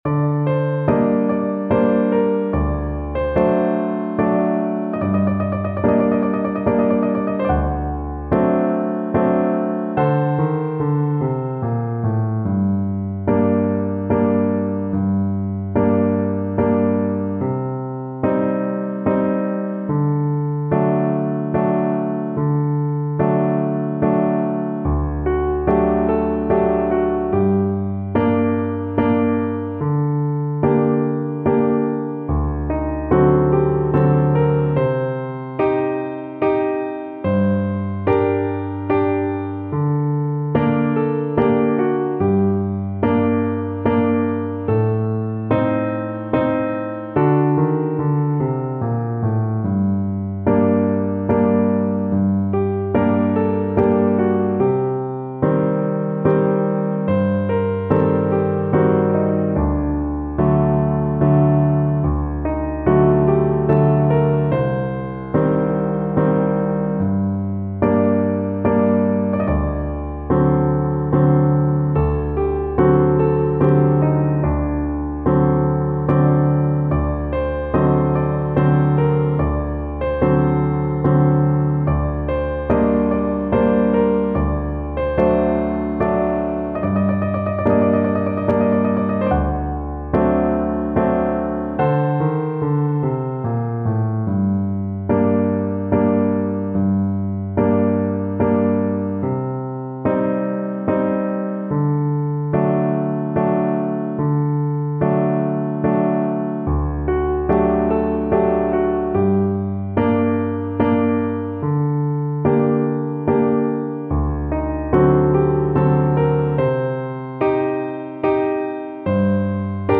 Steadily =c.110
3/8 (View more 3/8 Music)
D5-Eb6
Traditional (View more Traditional Voice Music)
world (View more world Voice Music)